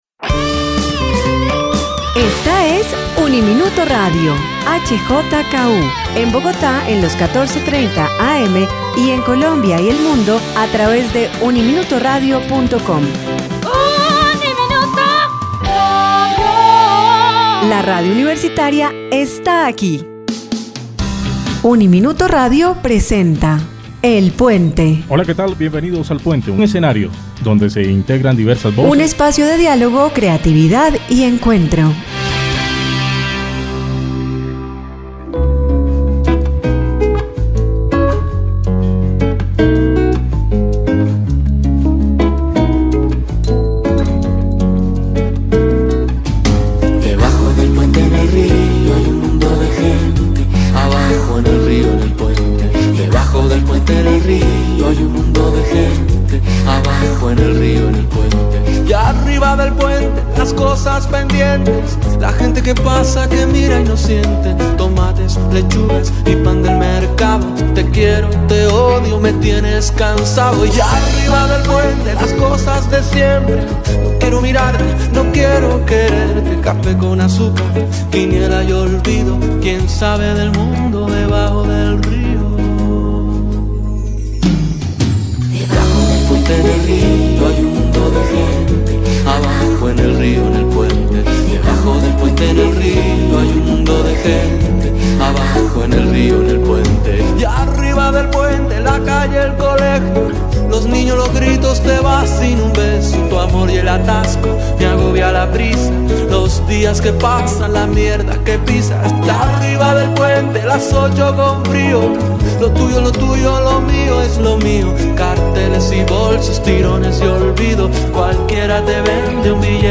En el programa el puente, contamos con la oportunidad de dialogar con un maravilloso invitado